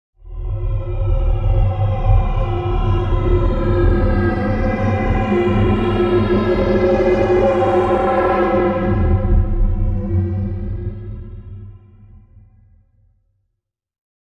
Som de fantasma 2.mp3